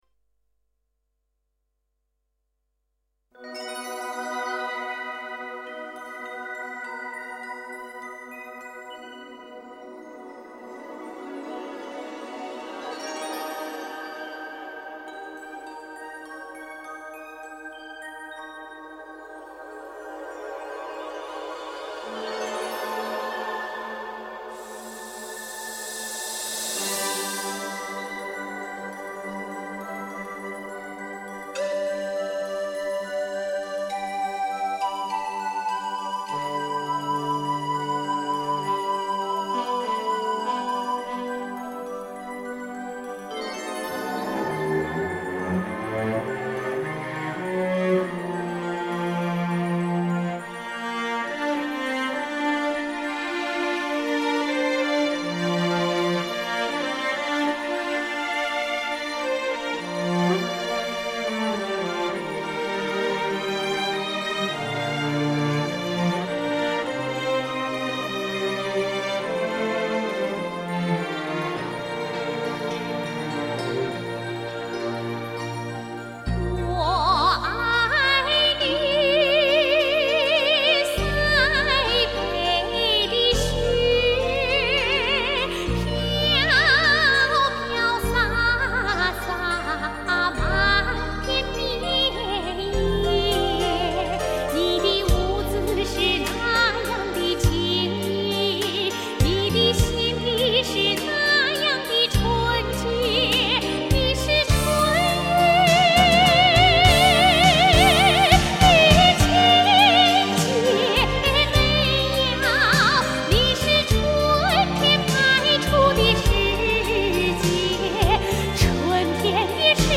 中国著名女高音歌唱家
专攻民族声乐